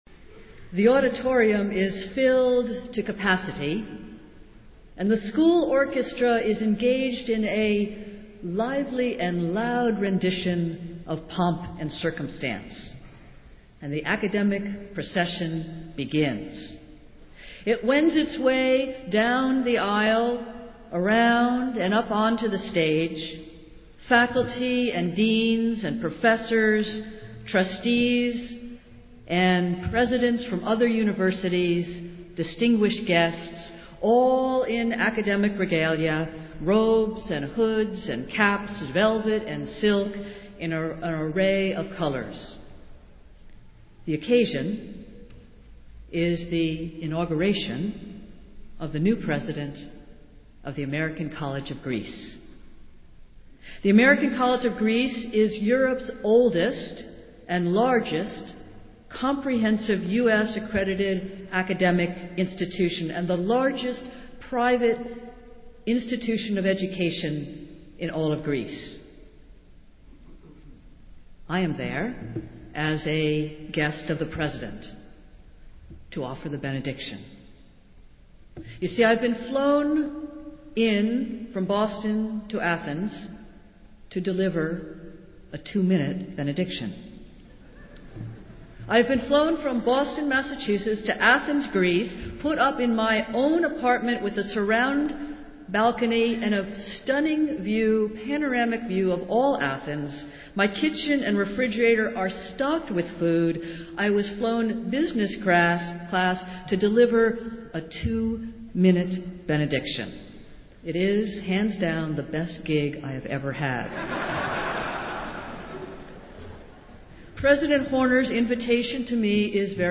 Festival Worship - Reformation Sunday